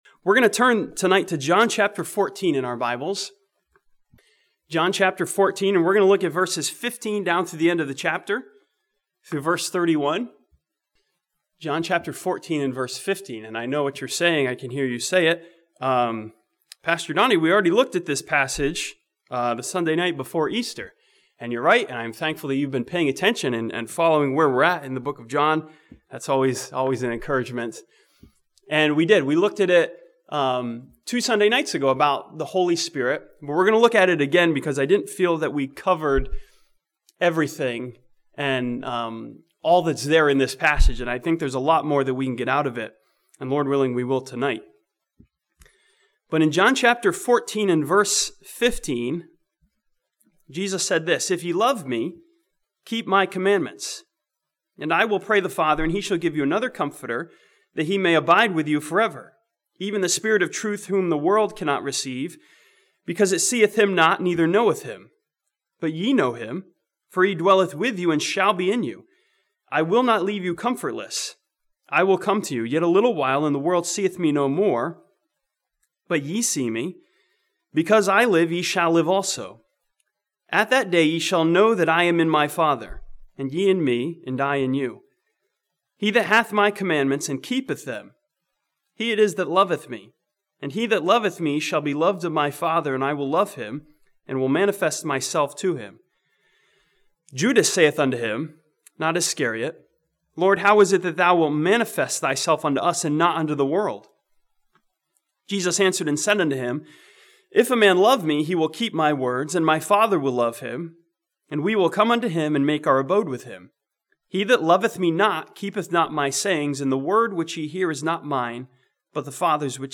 This sermon from John chapter 14 asks and answers the question "where's the love?" by study the words of Jesus Christ.